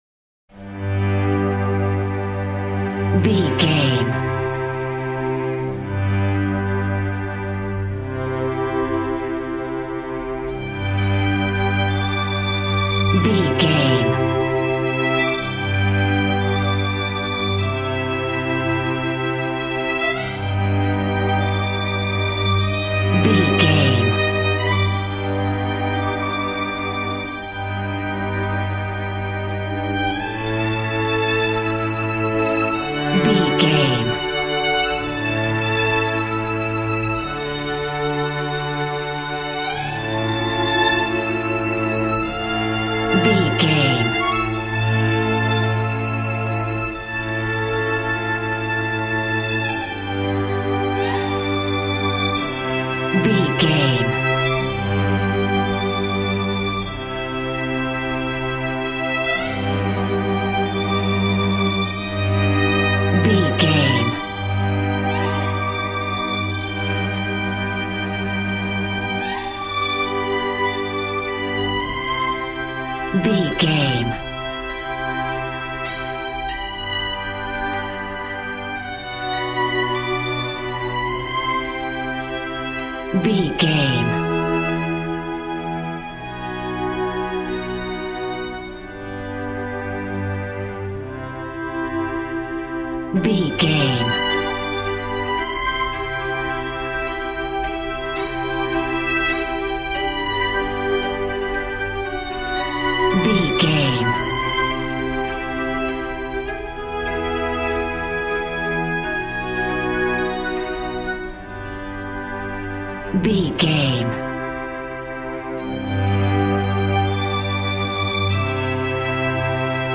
Ionian/Major
regal
cello
violin
brass